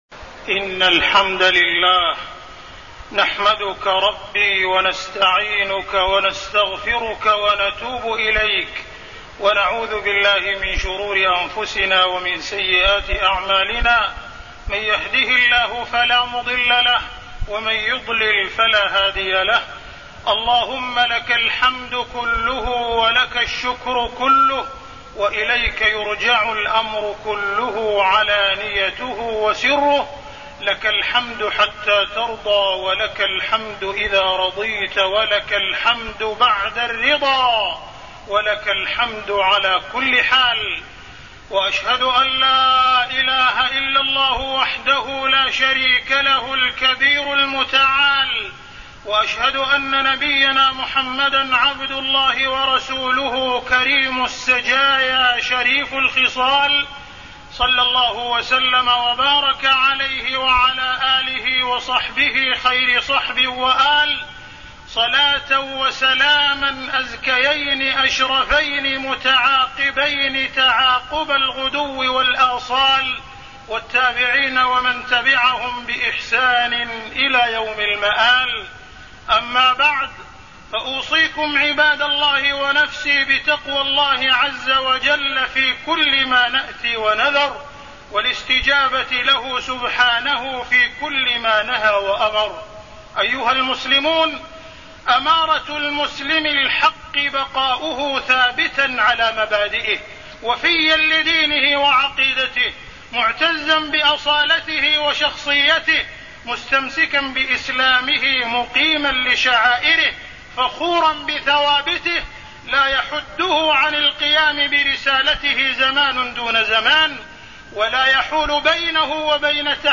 تاريخ النشر ٢١ ربيع الأول ١٤٢١ هـ المكان: المسجد الحرام الشيخ: معالي الشيخ أ.د. عبدالرحمن بن عبدالعزيز السديس معالي الشيخ أ.د. عبدالرحمن بن عبدالعزيز السديس الاجازة والسفر